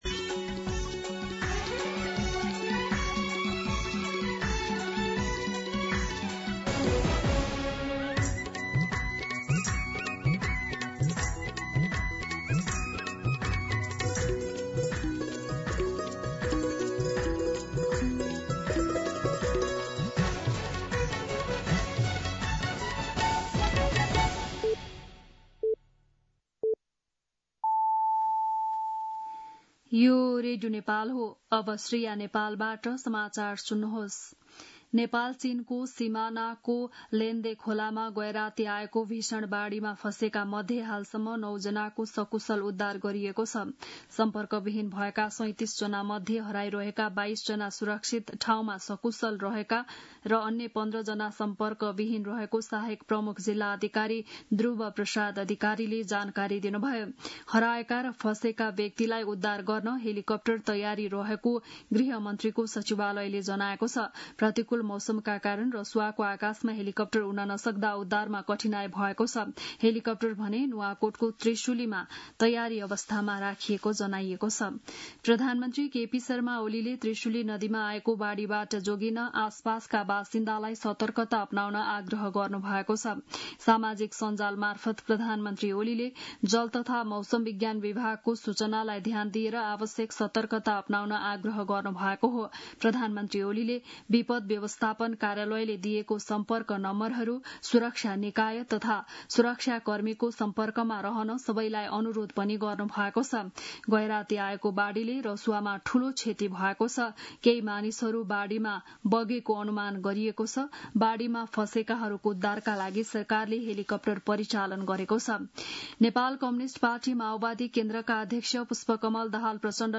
बिहान ११ बजेको नेपाली समाचार : २४ असार , २०८२
11am-News-24.mp3